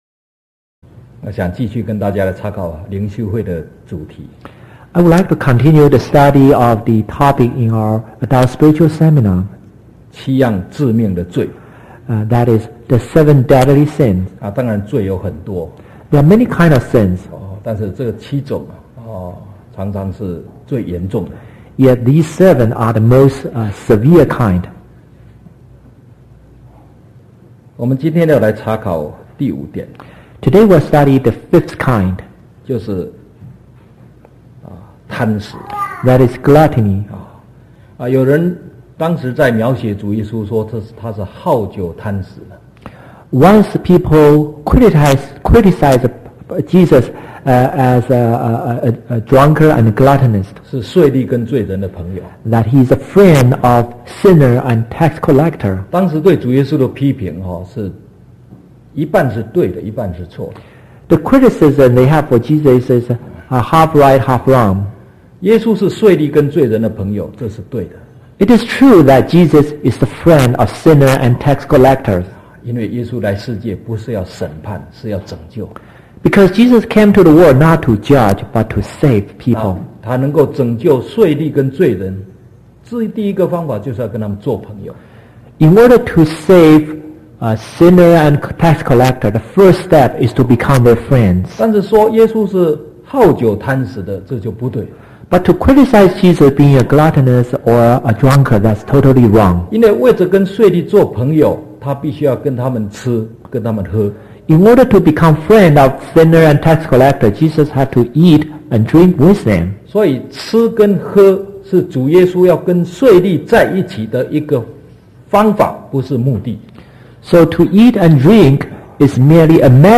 TJC True Jesus Church audio video sermons Truth Salvation Holy Spirit Baptism Foot Washing Holy Communion Sabbath One True God